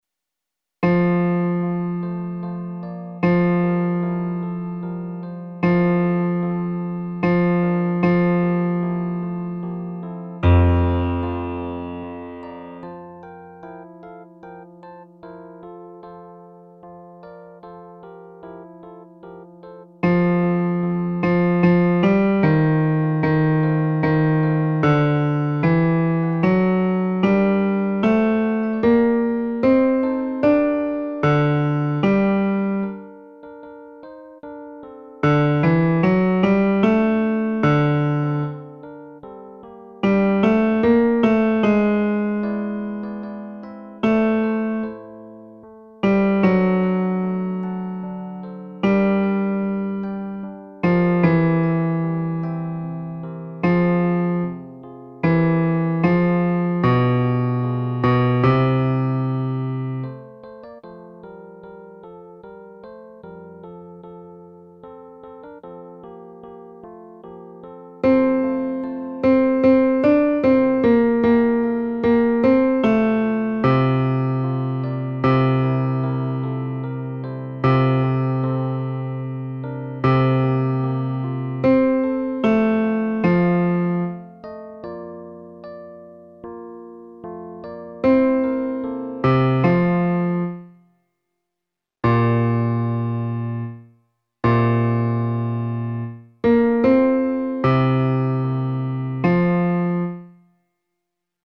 Bajo